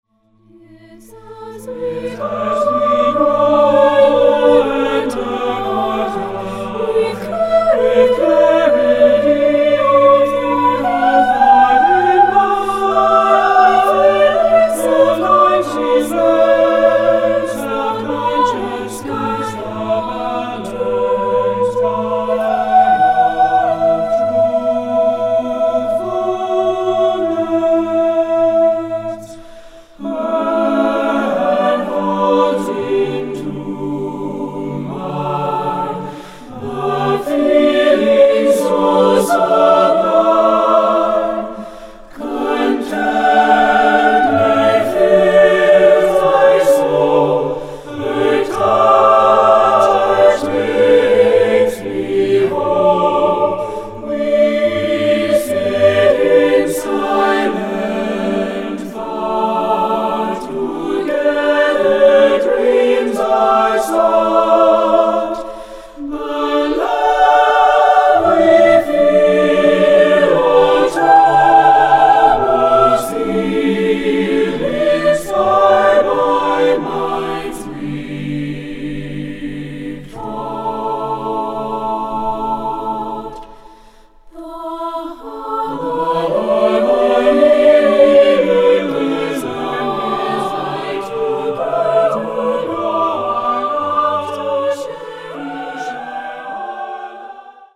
Choeur Mixte (SATB) a Cappella